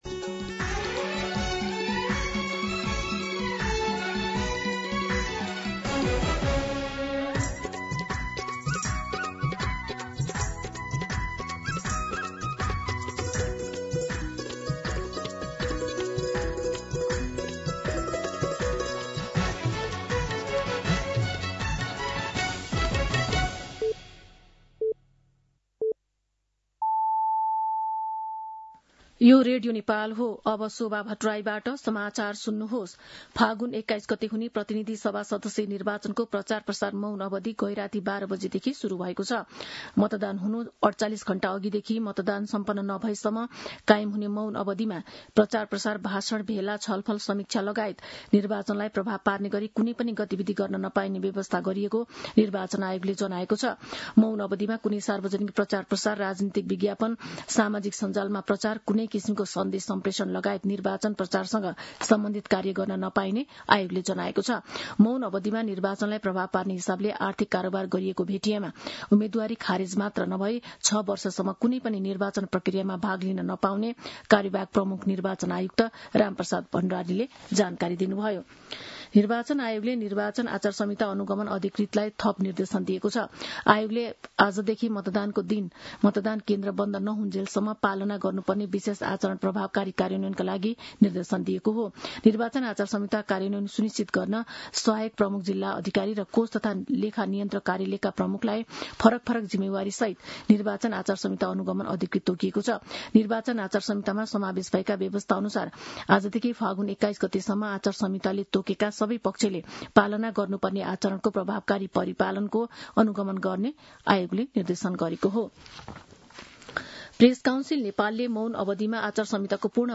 मध्यान्ह १२ बजेको नेपाली समाचार : १९ फागुन , २०८२